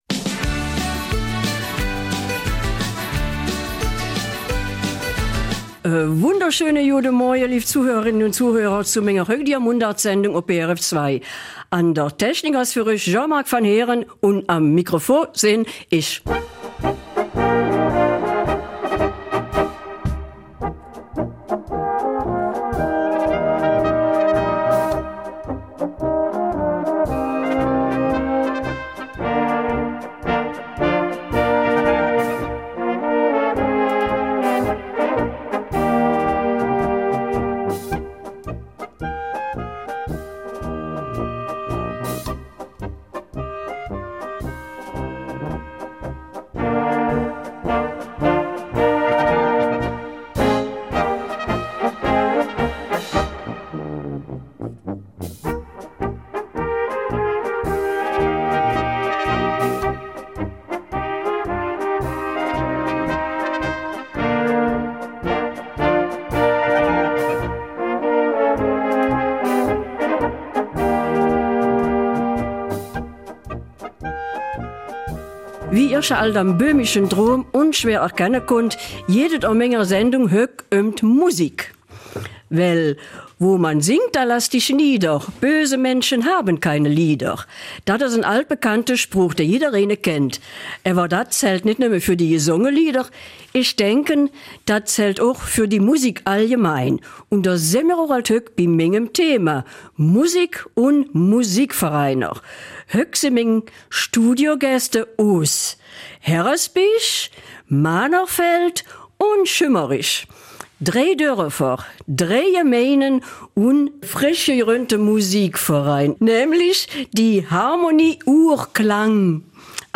Eifeler Mundart - 5. Oktober